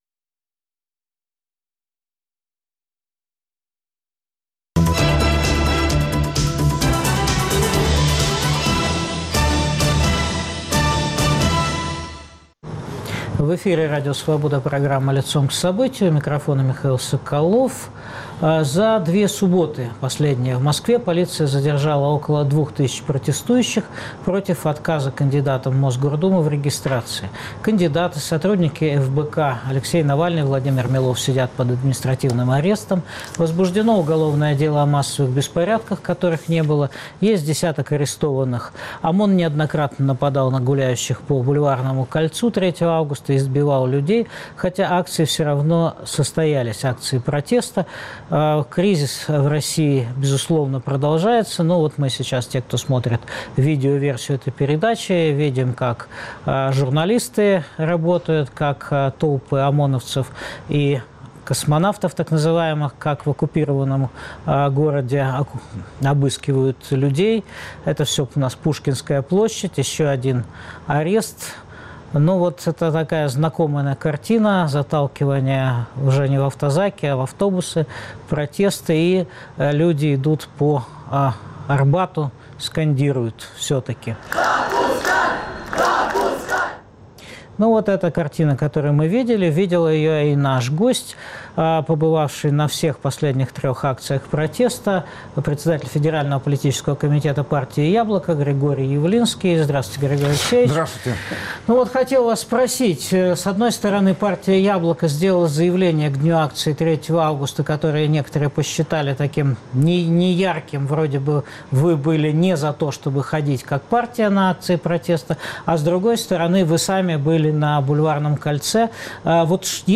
Какие 8 пунктов требований предлагает оппозиции председатель Политкомитеат партии "Яблоко"? В студии Радио Свобода - Григорий Явлинский.